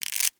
• Качество: высокое
звук ломающихся сухих спагетти